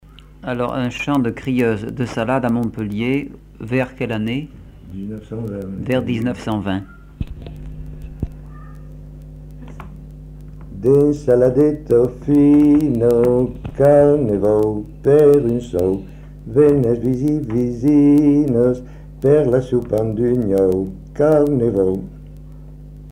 Chant de crieuse de salade à Montpellier